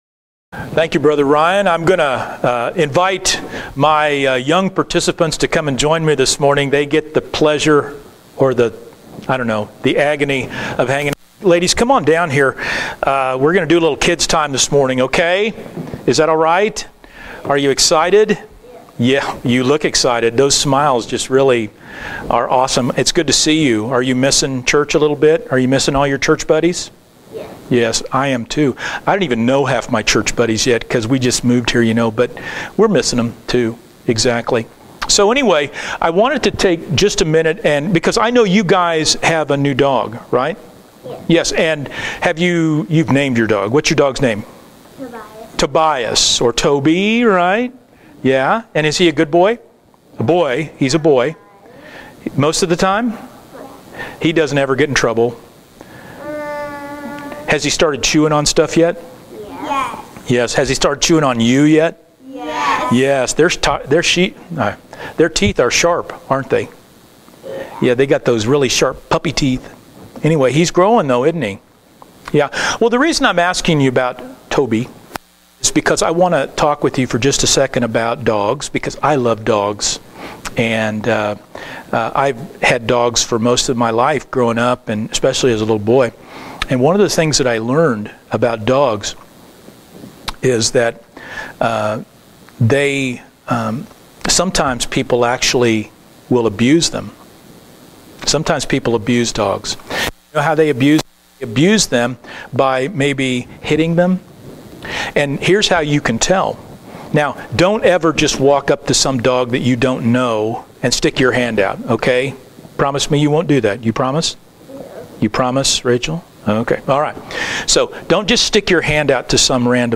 Sermon-only-_-Hope-of-the-Resurrection-Intensifies.mp3